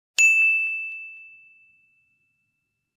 Sound Buttons: Sound Buttons View : Dingggg
ding-sound-effect_jcioY9i.mp3